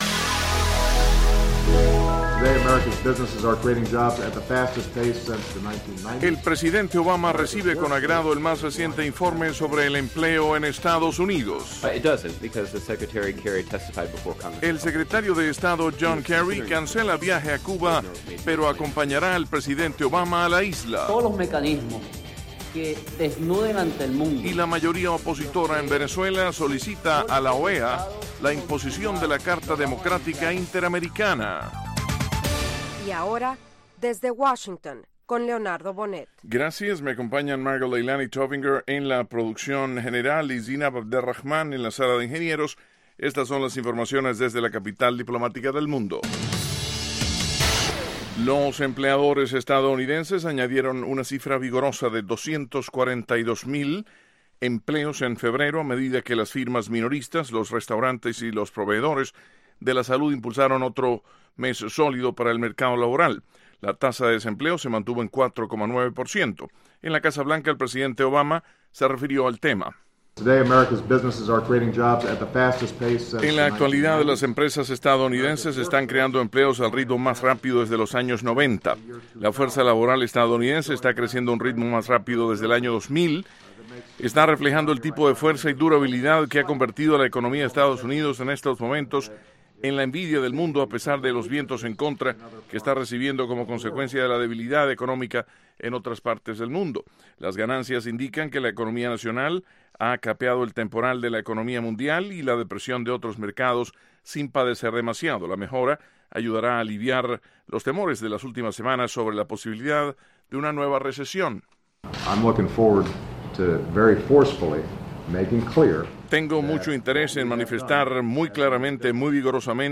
Diez minutos con las noticias más relevantes del día, ocurridas en Estados Unidos y el resto del mundo.